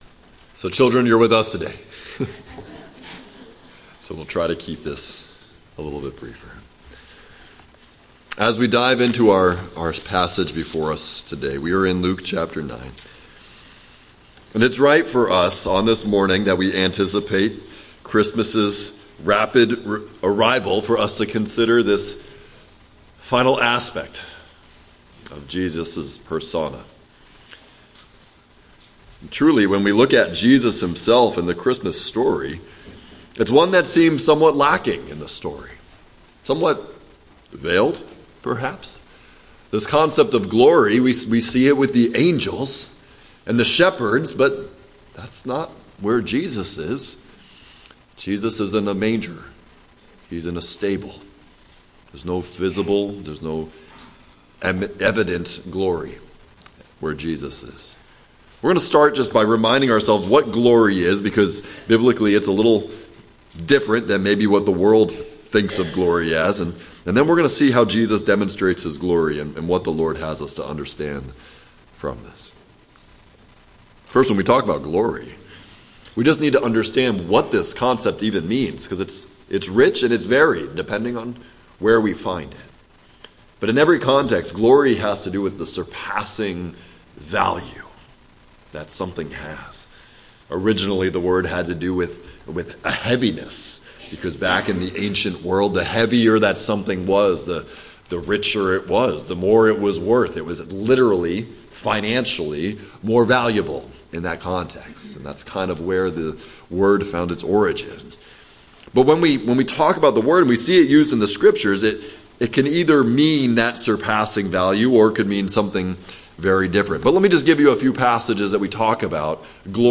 Luke: Christmas through the Disciples Eyes – Jesus as Glory - Waynesboro Bible Church